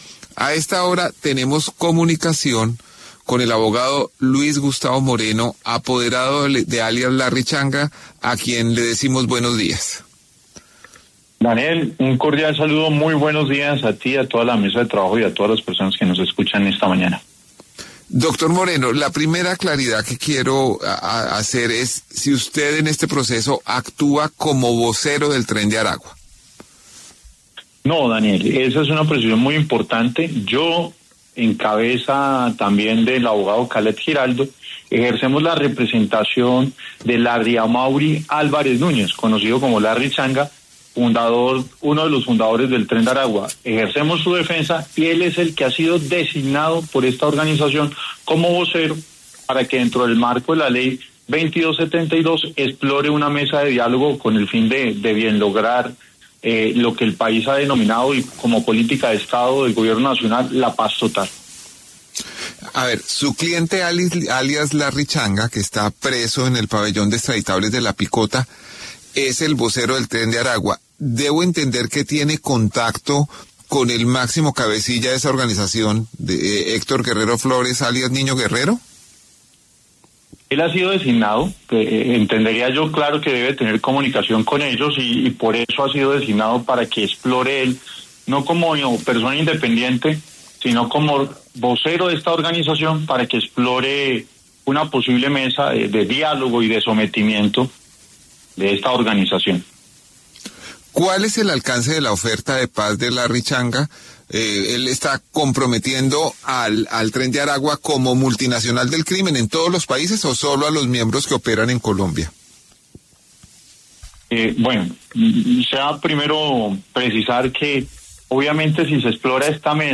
En conversación con El Reporte Coronell